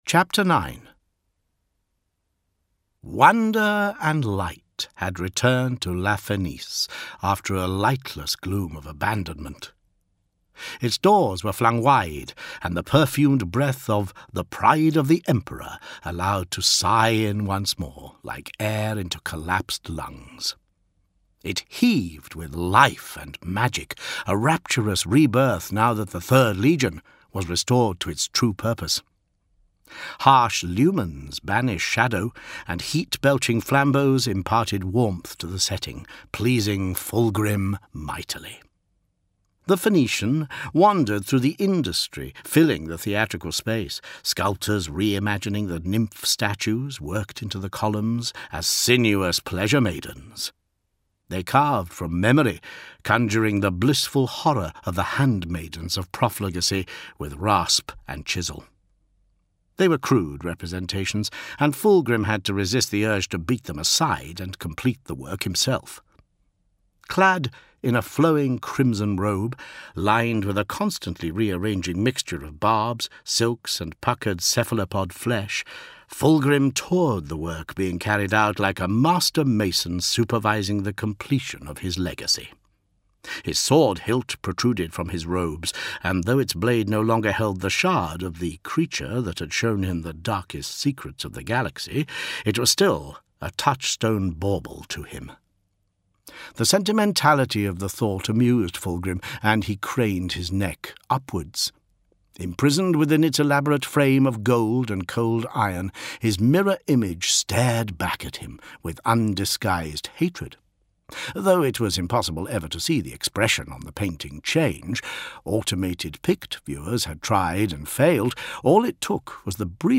Warhammer 40k – Angel Exterminatus Audio Book